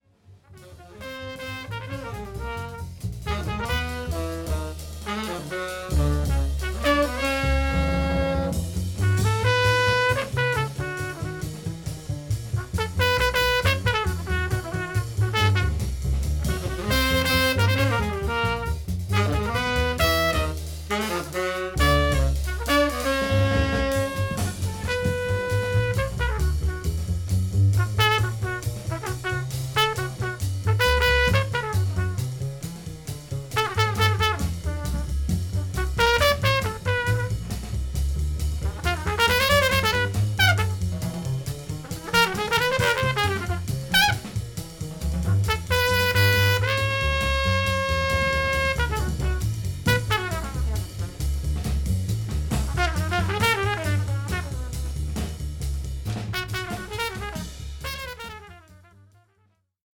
ソプラノ・サックスをプレイした初の作品でもあります。